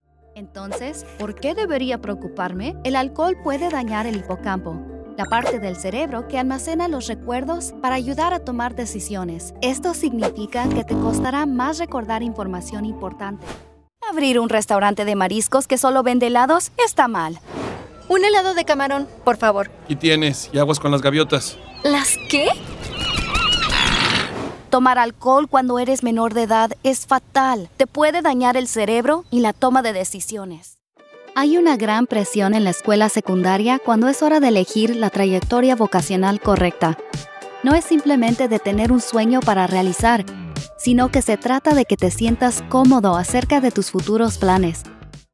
Bilingual Mexican American Texas Based Voice Actor
Demos
Spanish - Neutral
Mexican/Neutral